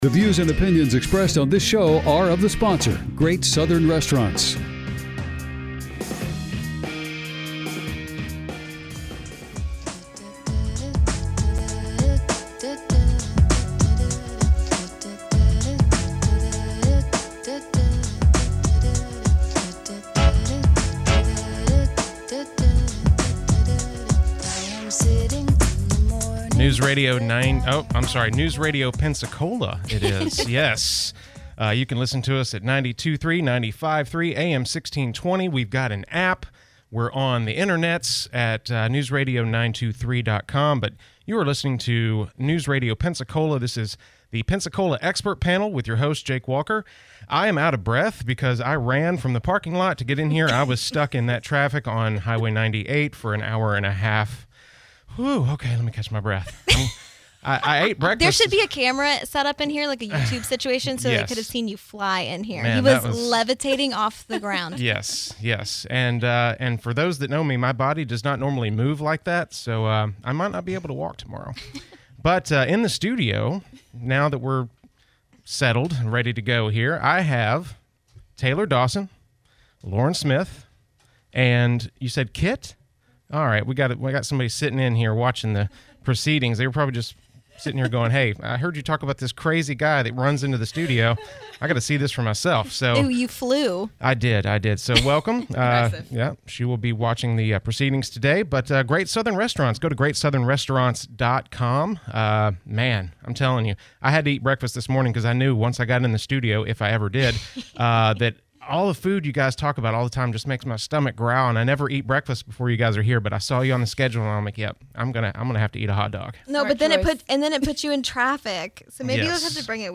are in the studio to highlight upcoming Atlas Beverage Classes and specials for the Mother's and Father's Day holidays.